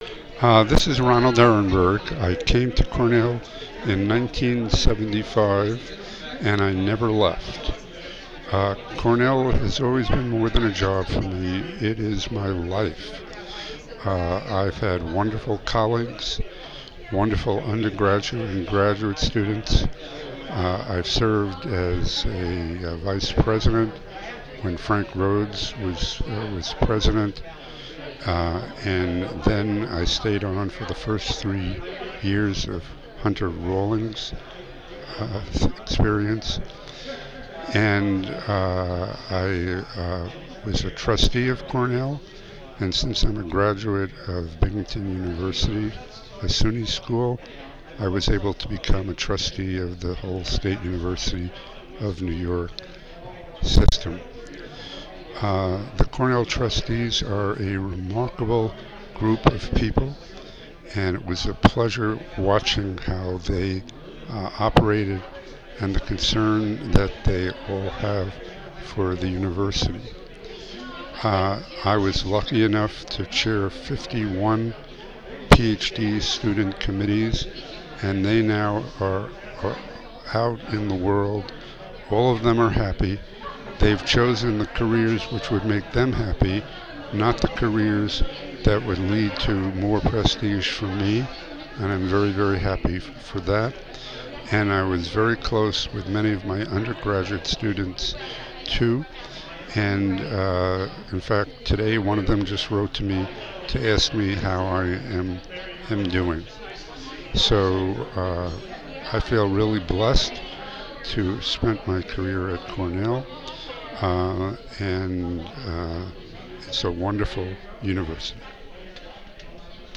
At the annual retirement reception in May, retired faculty have the opportunity to record a memory from their time at Cornell.
Retired faculty member recording a memory from their time at Cornell.